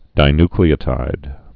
(dī-nklē-ə-tīd, -ny-)